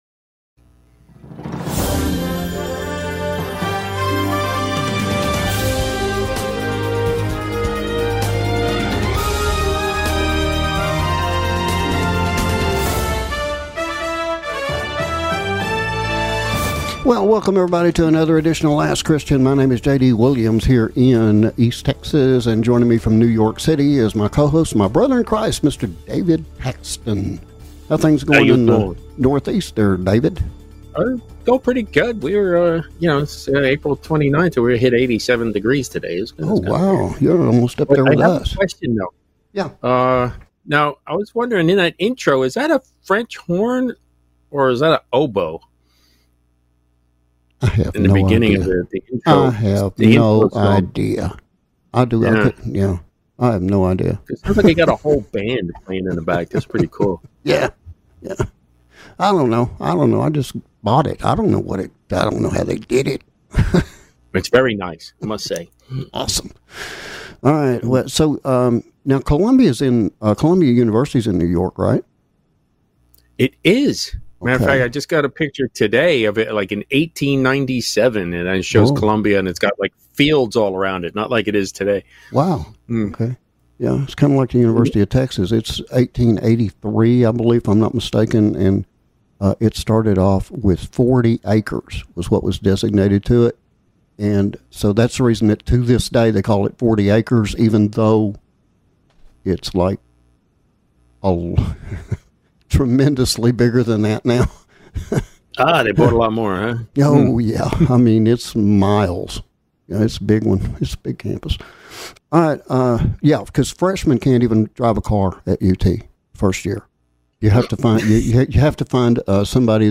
We also apologize for the Audio issues experienced primarily in the first half hour of our International Syndicated Presentation.